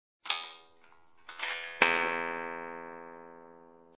Descarga de Sonidos mp3 Gratis: videojuegos 5.